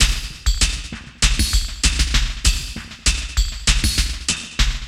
98WAGONLP3-L.wav